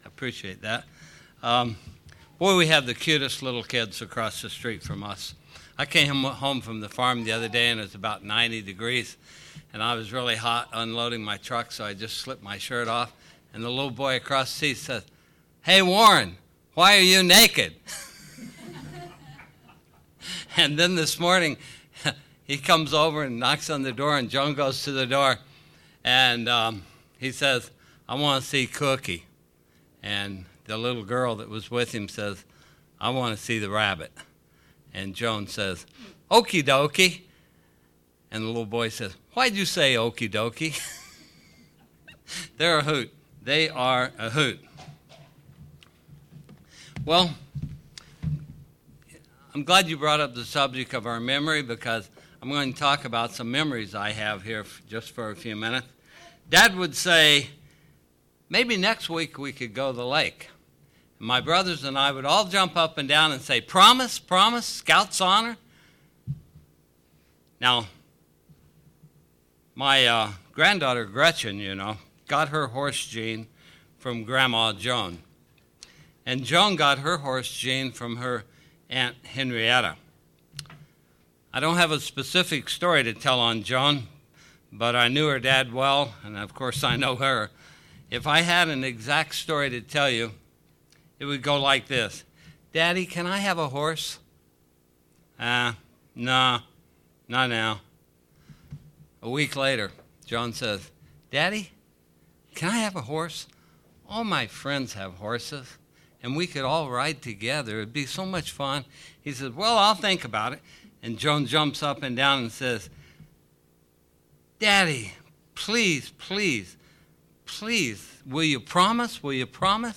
All throughout the bible, God has promised mankind wonderful things. In this sermon, God's promises are shown.